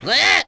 Lakrak-Vox_Attack5.wav